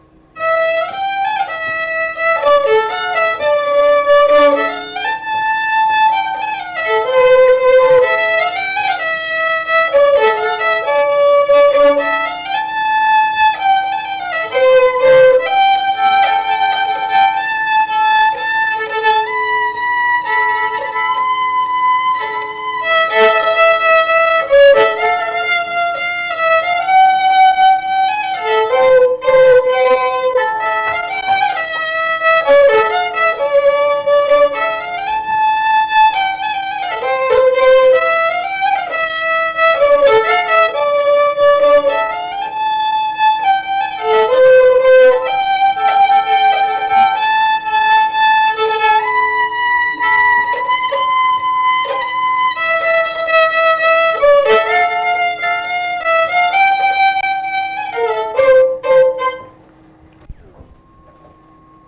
Polka · LMTA Muzikinio folkloro archyvas · omeka
instrumentinis
akordeonas
LMTA Mokslo centro muzikinio folkloro archyvas